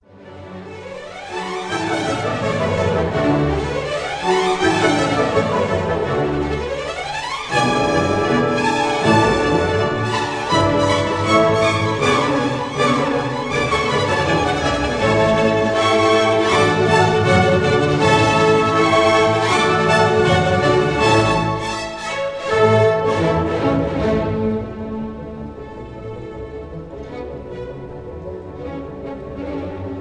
conductor
Recorded in the Kingsway Hall, London